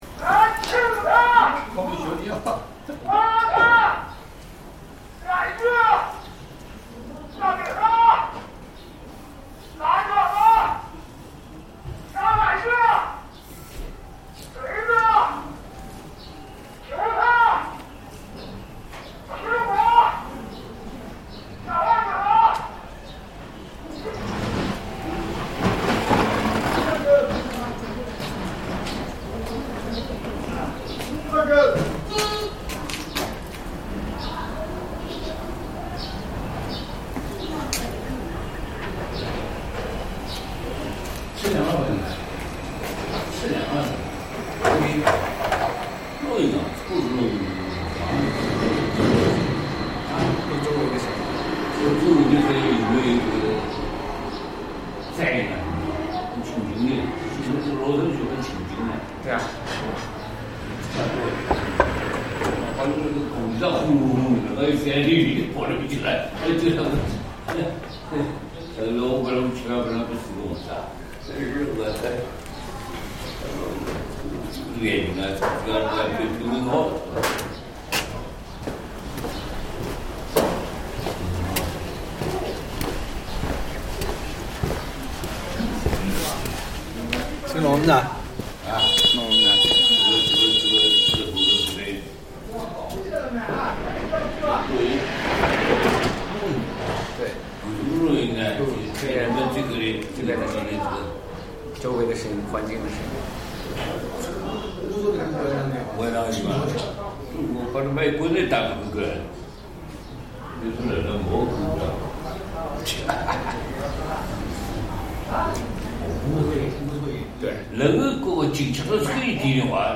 At the junction of narrow alleys, elderly voices drift from homes, chatting over lunch in refined Yangzhou dialect, even more elegant than Suzhou’s. Residents shuffle by in slippers, while a peddler’s calm calls echo faintly. Without the Yangzhou accent, this soundscape would likely mirror the daily life most people heard in the past.